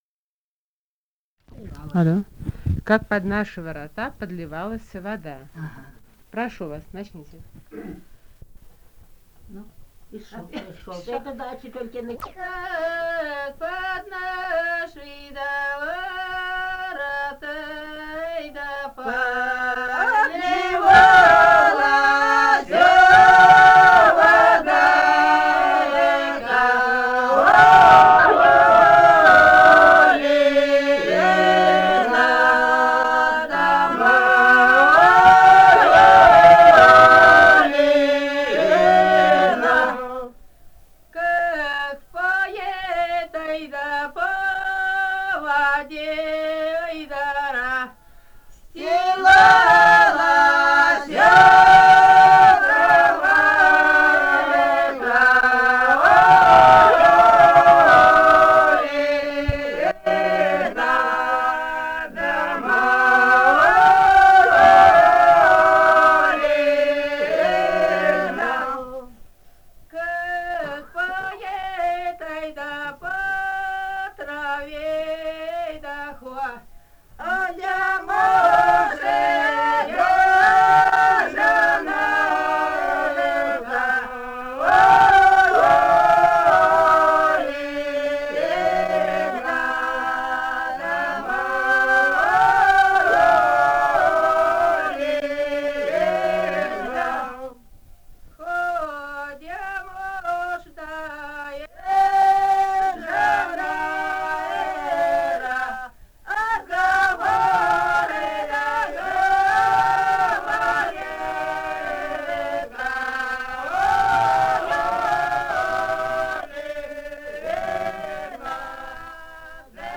Этномузыкологические исследования и полевые материалы
«Как под наши да ворота» (весенняя хороводная).
Алтайский край, с. Михайловка Усть-Калманского района, 1967 г. И1001-13